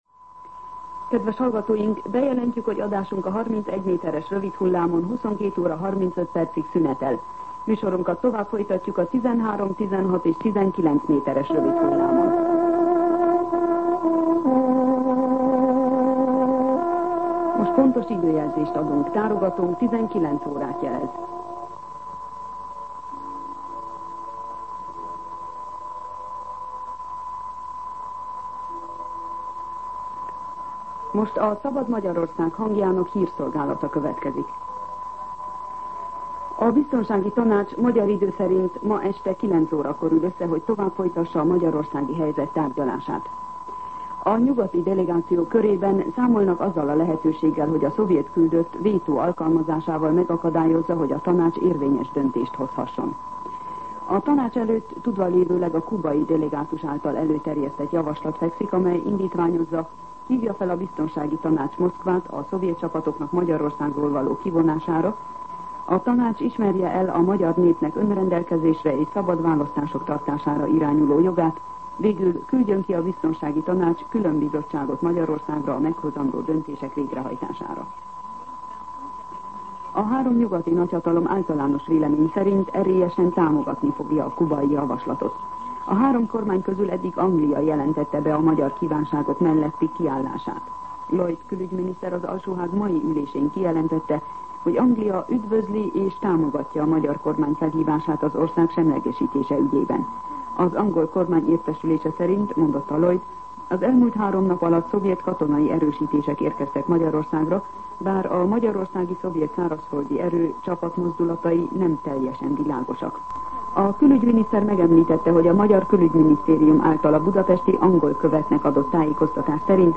19:00 óra. Hírszolgálat